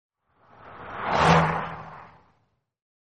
carPass0.mp3